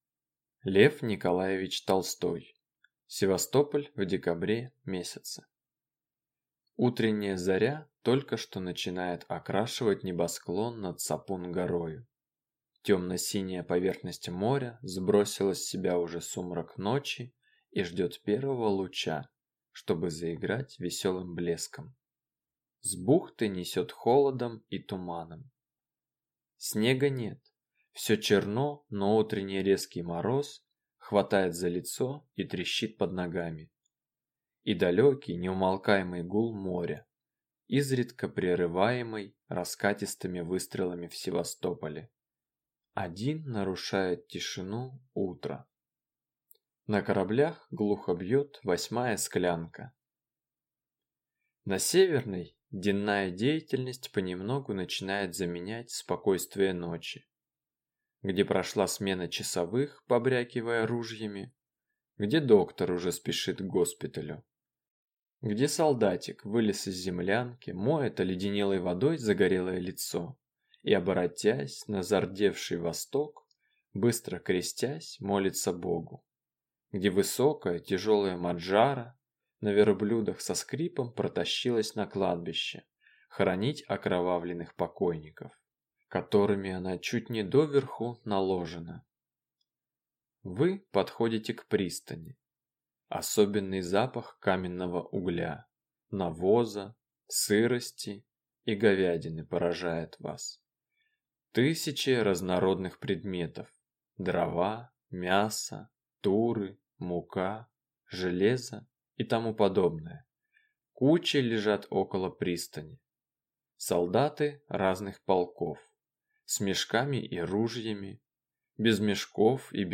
Аудиокнига Севастополь в декабре месяце | Библиотека аудиокниг